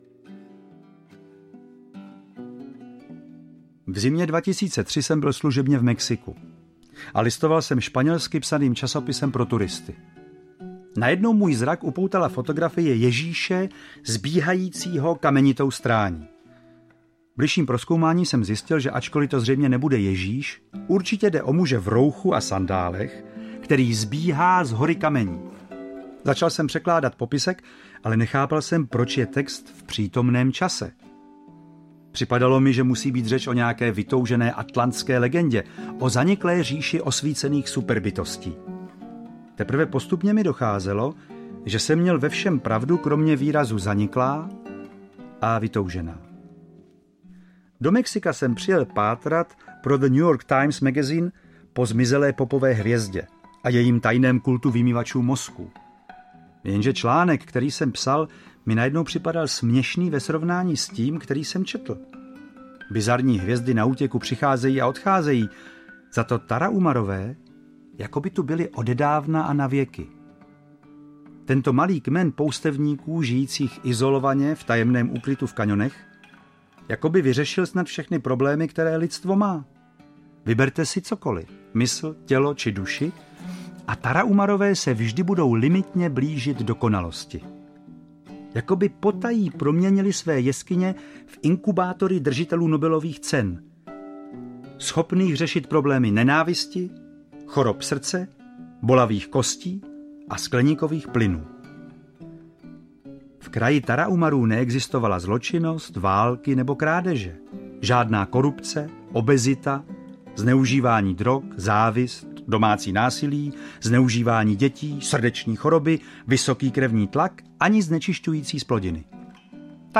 Ukázka z knihy
• InterpretJaroslav Dušek